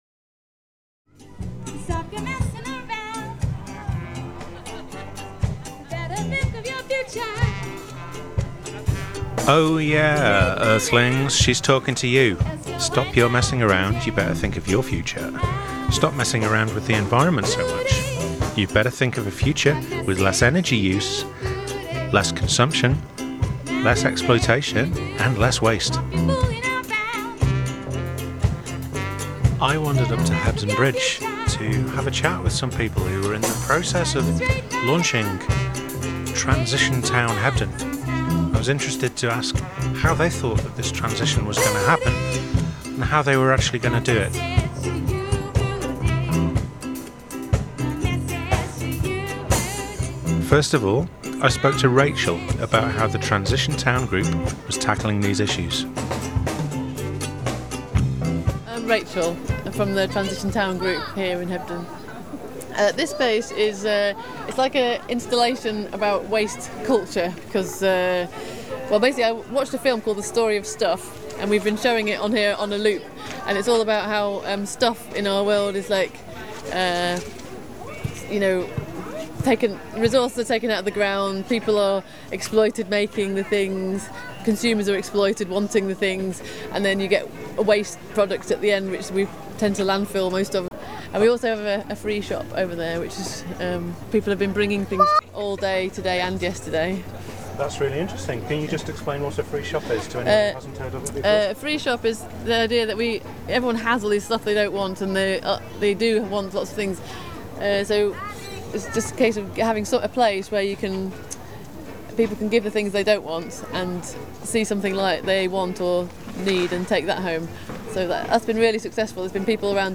This is the 2nd of 3 audio reports from the Transition Town Launch.